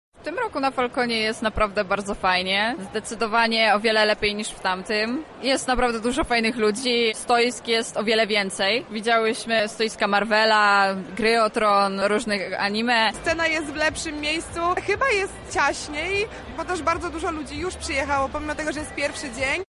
Konwentowicze podzielili się z nami swoimi wrażeniami.
Konwentowicze_sonda